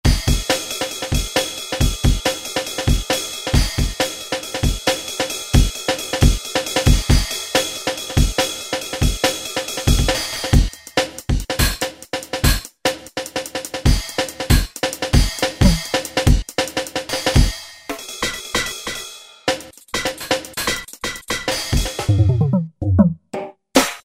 006 Jungle SET Classic Jungle/Drum & Bass kit.
006 Jungle SET.mp3